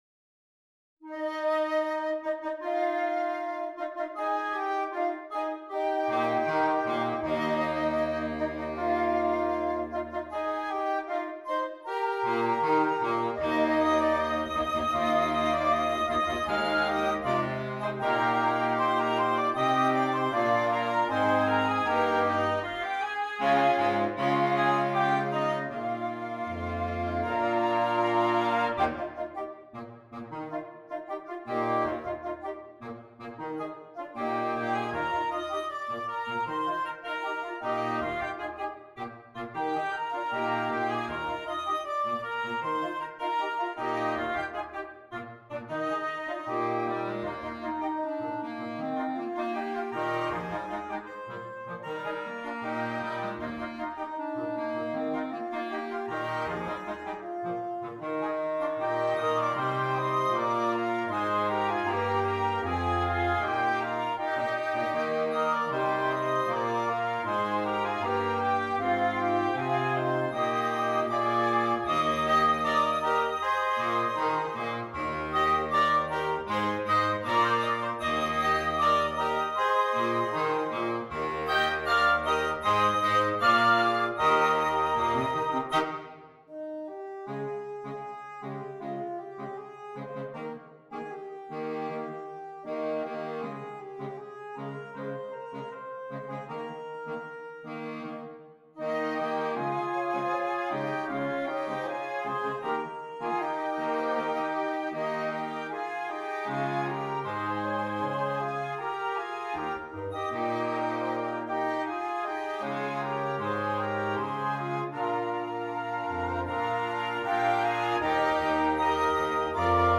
Voicing: Flexible Woodwind Quintet